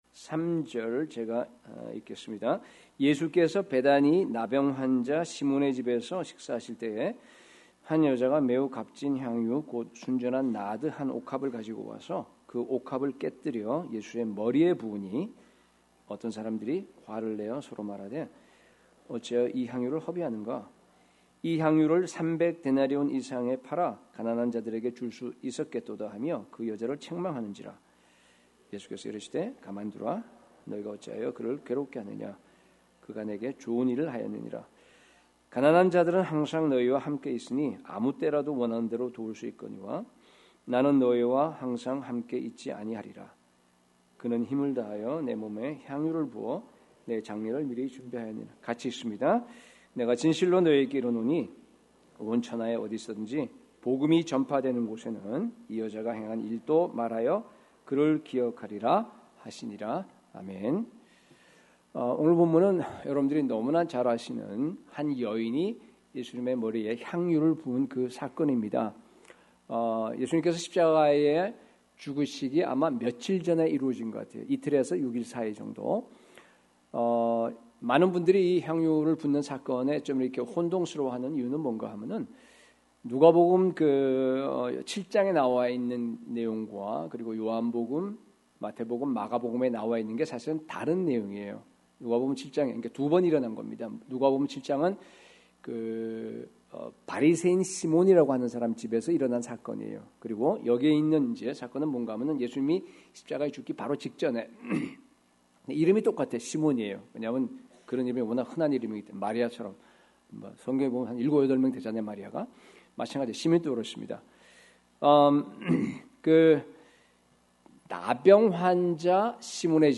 Like this: Like Loading… 2018년 주일설교 2026년 전교인 수련회 찬양 플레이 리스트 각종 신청서 2025년 헌금내역서 신청서